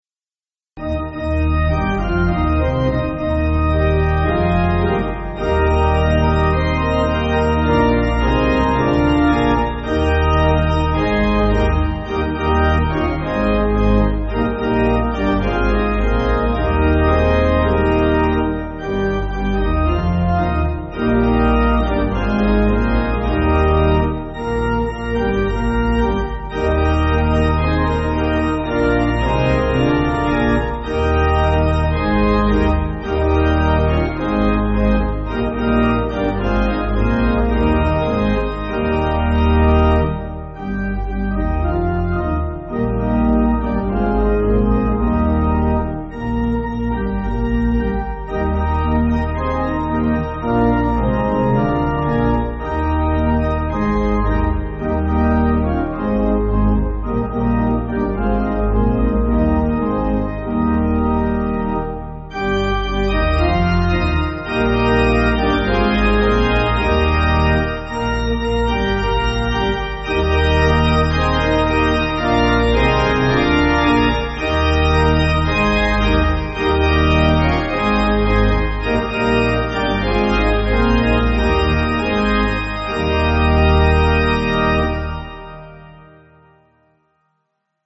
African-American Spiritual
Organ